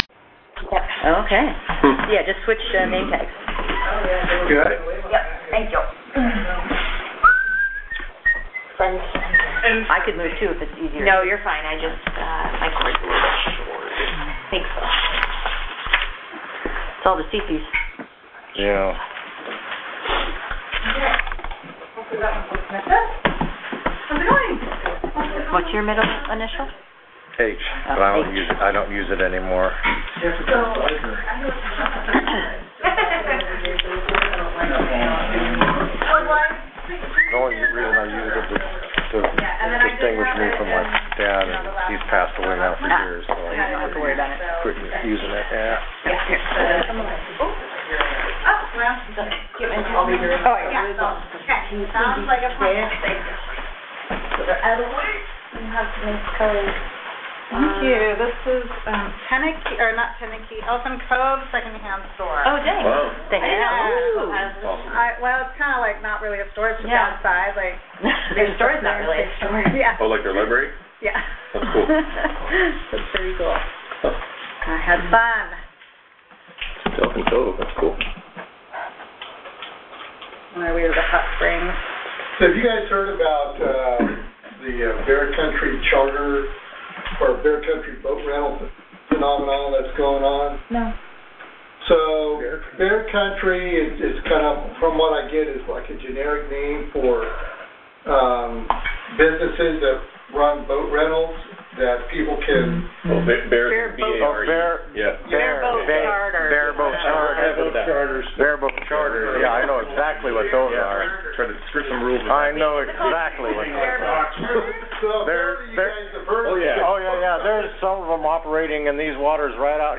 City Council General Meeting | City of Gustavus Alaska